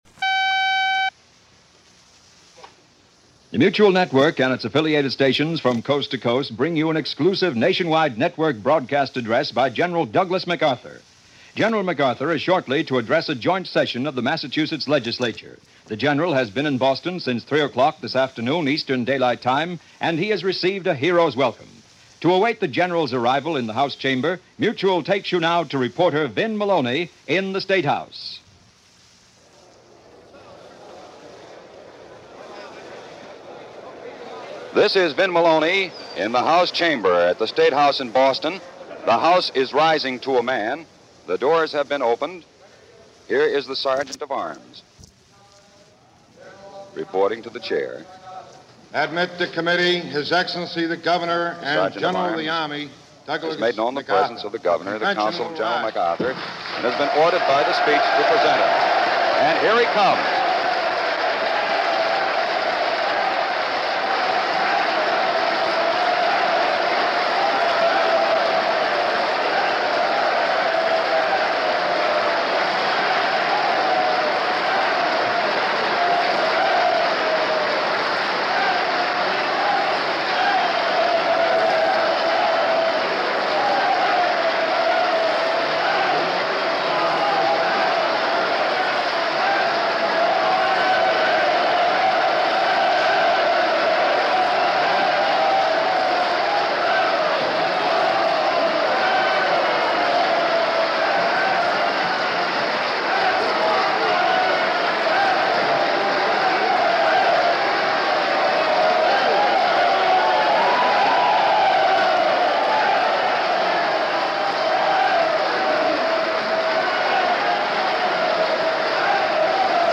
MacArthur Addresses The Massachusetts State Legislature - July 25, 1951 - Past Daily After Hours Reference Room - broadcast by MBS